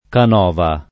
kan0va o k#nova secondo i casi] top. e cogn. — kan0va vari top. settentr. e un diffuso cogn. pure settentr. (portato fra l’altro dallo scultore Antonio C., 1757-1822), da una forma dial. per «casa nuova» — k#nova pochi top. e un raro cogn., solo tosc., dall’omonimo s. f. col sign. di «bottega» e sim. (analogam. al più diffuso top. e cogn. Caneva [k#neva], tratto da una var. settentr. della stessa voce)